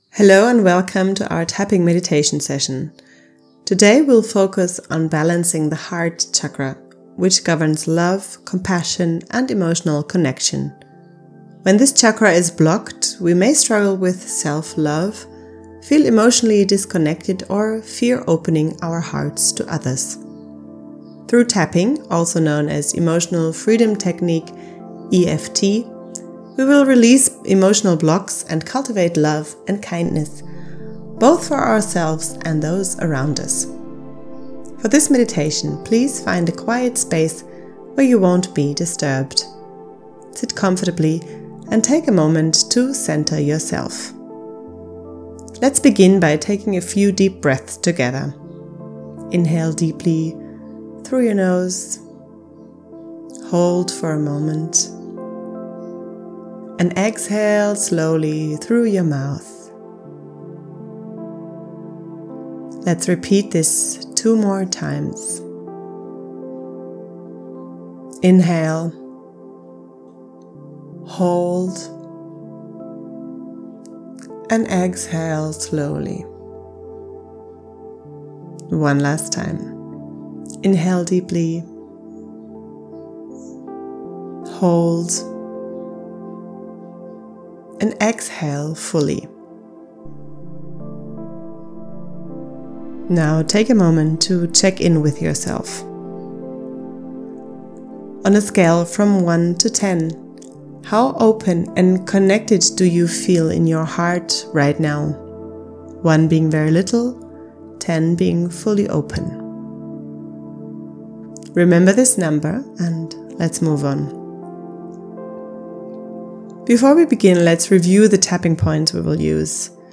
Heart-Tapping-Meditation.mp3